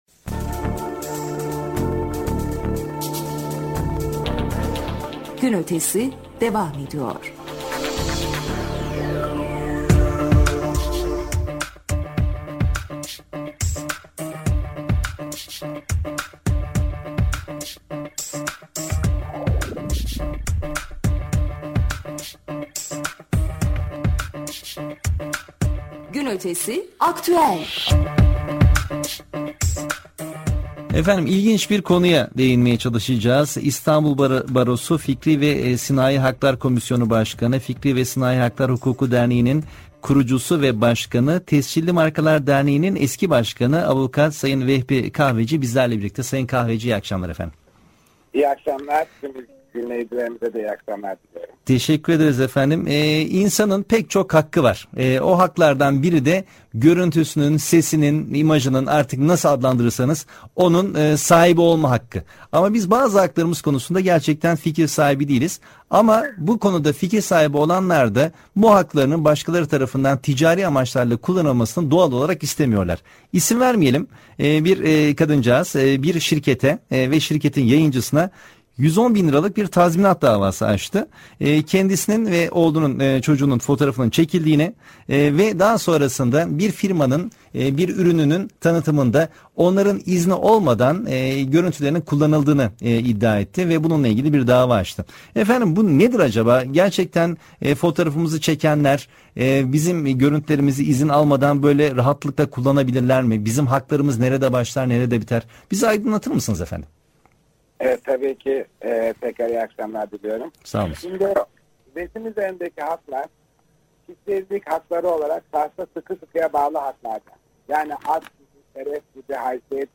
canl� telefon ba�lant�s� konu�u